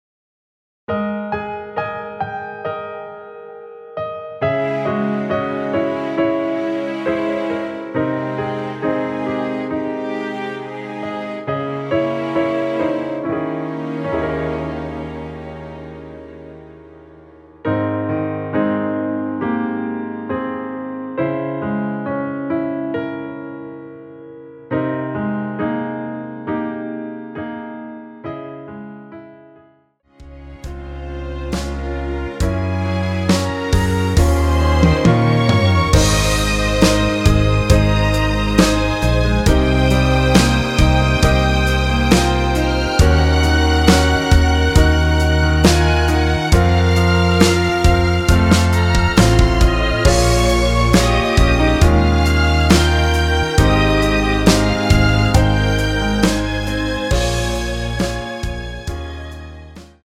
Ab
앞부분30초, 뒷부분30초씩 편집해서 올려 드리고 있습니다.
위처럼 미리듣기를 만들어서 그렇습니다.